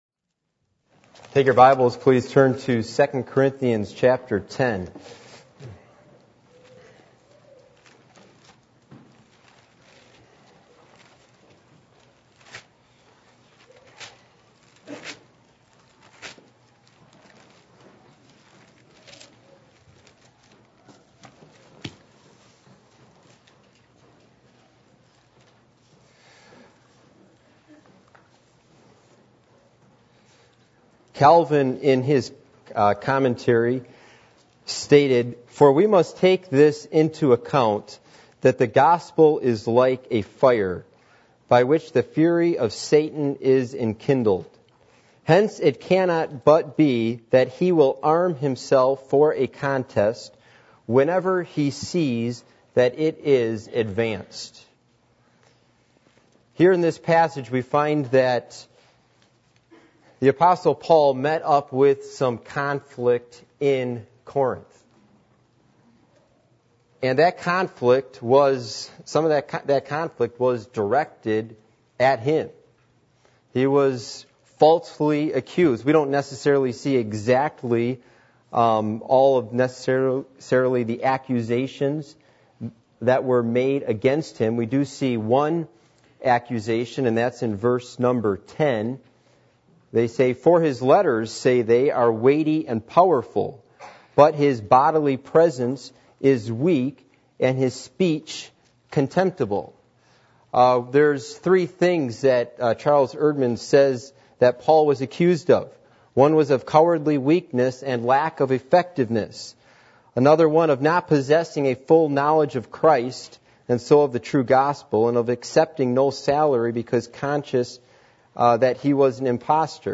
Passage: 2 Corinthians 10:1-5 Service Type: Sunday Evening